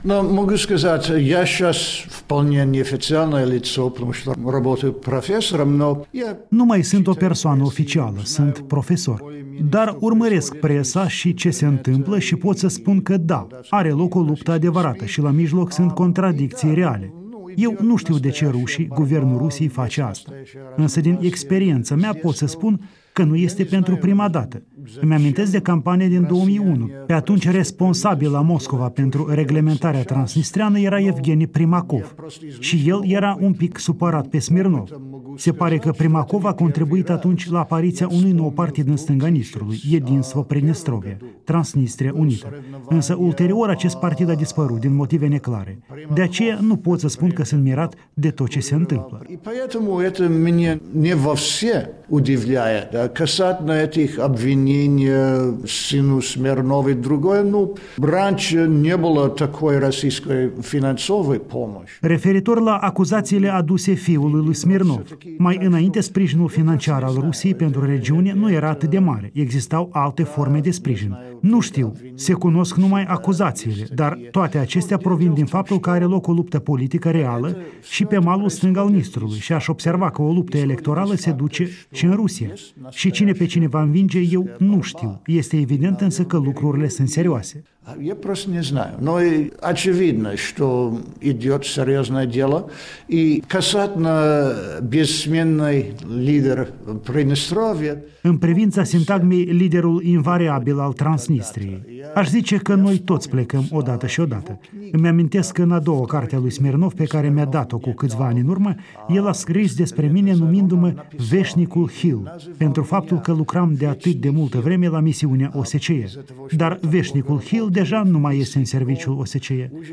Un interviu cu ambasadorul William Hill